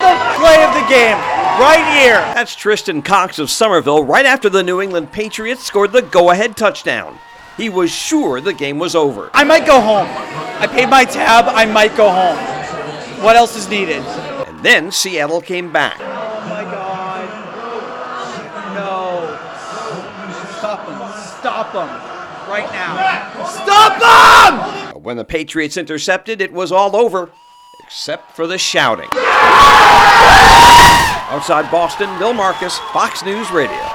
Die hard New England Patriots fans watching the 4th quarter of the Super Bowl Sunday night in a bar in Somerville, Massachusetts.